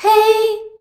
HEY     A.wav